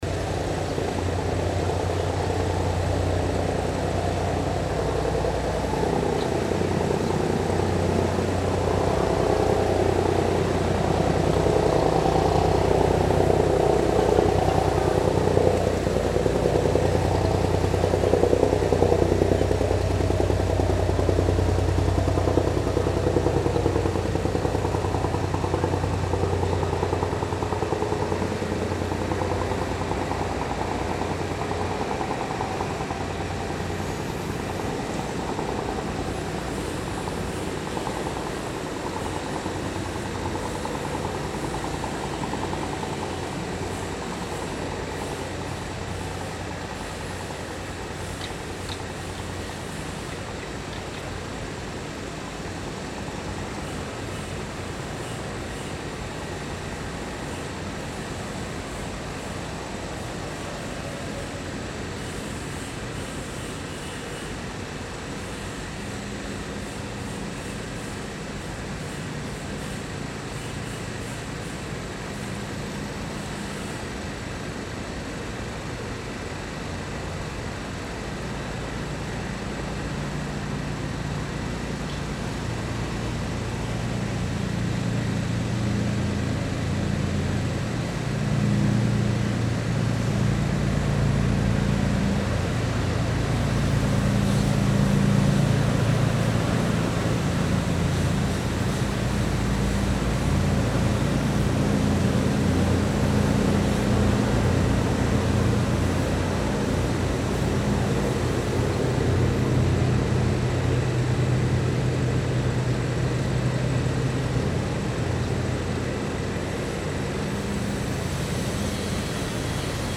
Tags Boat , Engine , Ocean , 引擎 , 海洋 , 船
The Ap Lei Chau Bridge is the only road leading to Ap Lei Chau, spanning the Aberdeen Channel and connecting the eastern parts of Aberdeen and Ap Lei Chau. The recording was made under the bridge near Ap Lei Chau Park, where you can hear the sounds of boats passing through the channel, nearby construction and engine, and the acoustic effect of sounds echoing under the bridge.
錄音器材 Recording Device: Tascam Portacapture X8 w/ Clippy EM272 錄音方法 Recording Method: 三腳架 Tripod
錄音於鴨脷洲公園旁的橋底位置進行，可以聽到船隻駛過海峽、附近工程及引擎的聲音，和聲音因為在橋底下反彈的空間感。